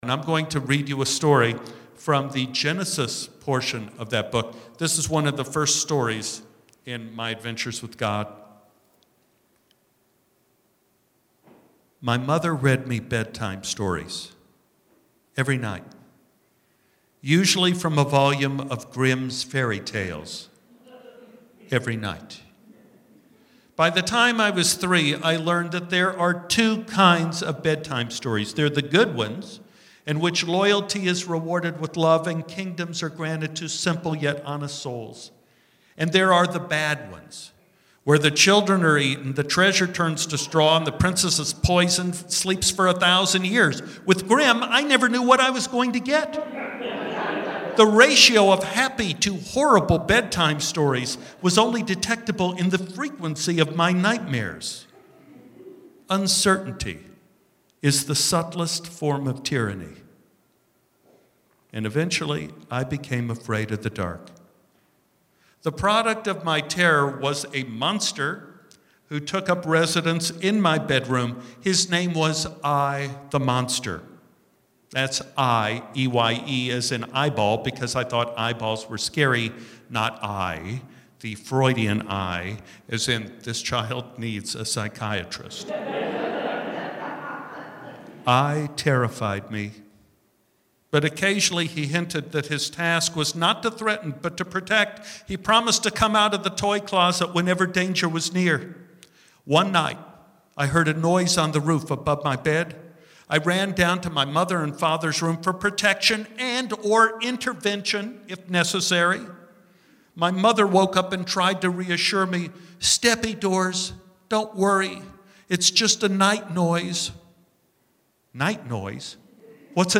Stephen Tobolowsky reading a story from his book “My Adventures with God”, recorded live during his November 15th performance at the Brewer Performing Arts Center.
Tobolowsky-Santa-conspiracy-room-mix-edit.mp3